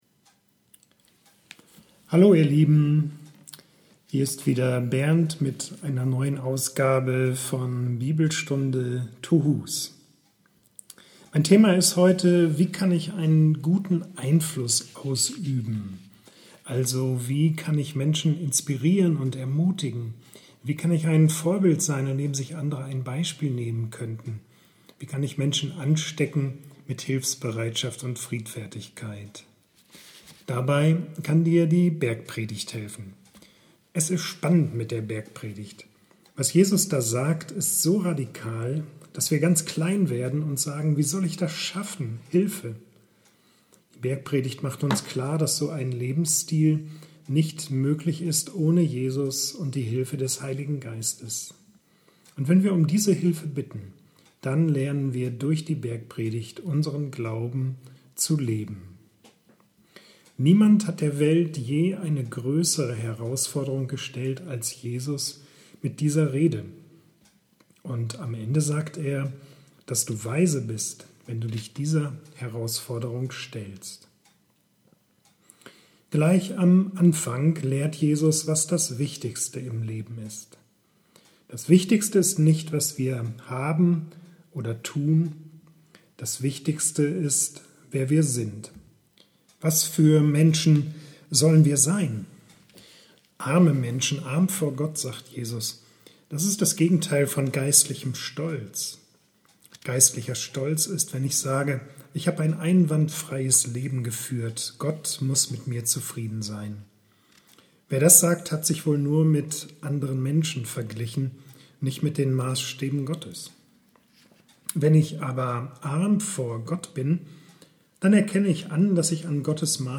Landeskirchliche Gemeinschaft und Jakobi-Kirche Hanstedt laden zur Christvesper in Brackel ein